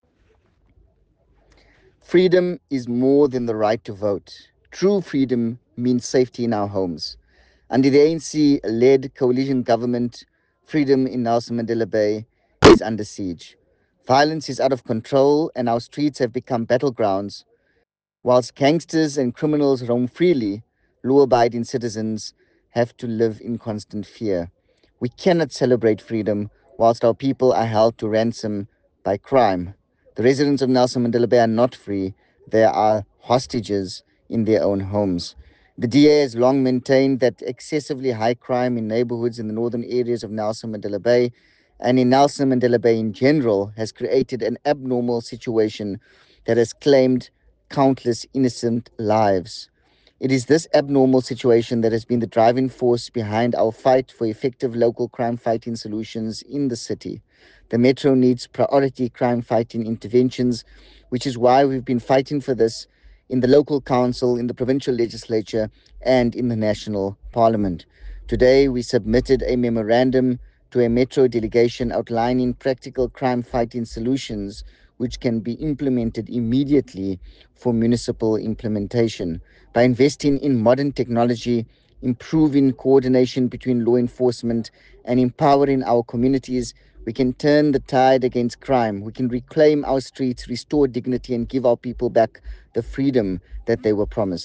soundbite delivered by Yusuf Cassim MPL, DA EC Provincial Chairperson, at the Metro Police Station in Chatty, Gqeberha after a Freedom Day march against crime in Nelson Mandela Bay.